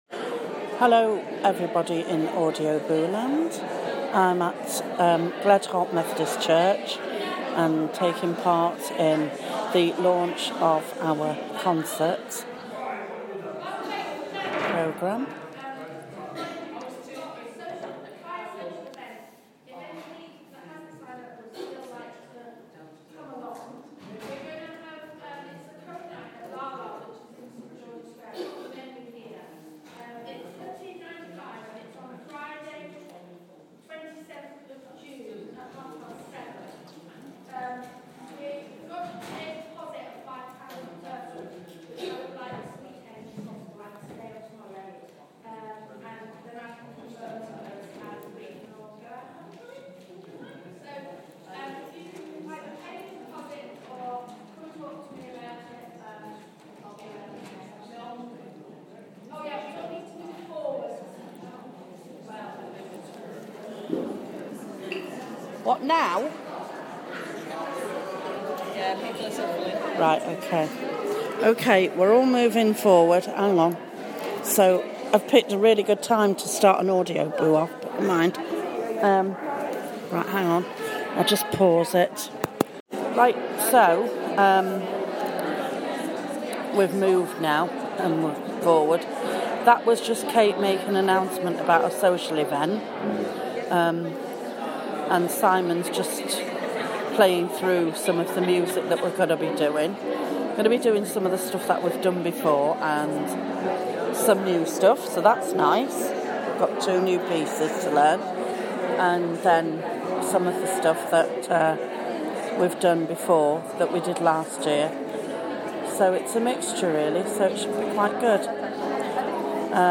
First Choir Rehearsal